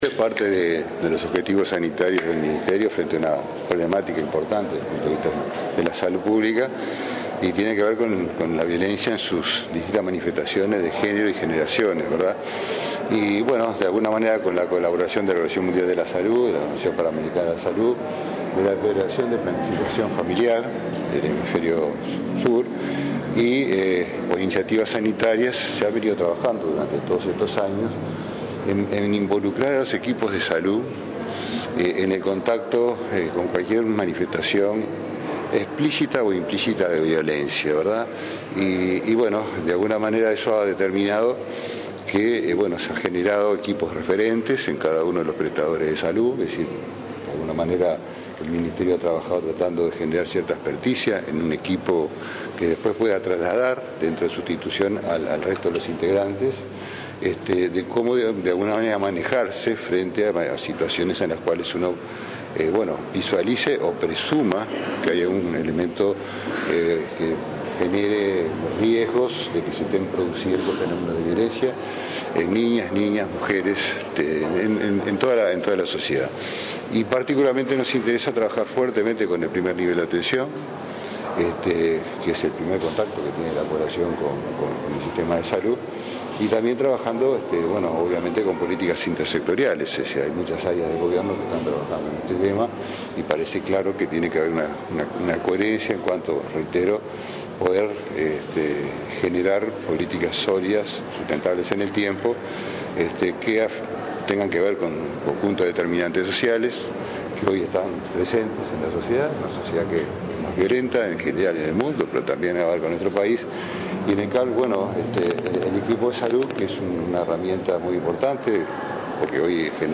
“El manual es parte de los objetivos sanitarios del Ministerio frente a una problemática importante para la salud pública”, afirmó el ministro Jorge Basso, en el lanzamiento del manual de atención a mujeres que han sufrido actos de violencia de pareja. Se recogen experiencias de otros países, se adaptan a la situación de Uruguay y se involucra al personal de salud para brindar la atención adecuada en cada caso.